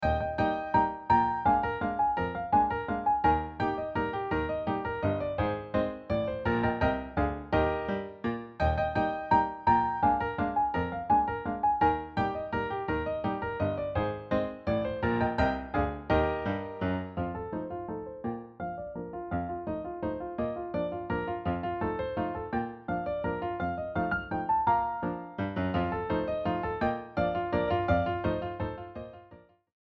Folk music for piano.
Folk music; parlor music; landler
Piano solo Year of arrangement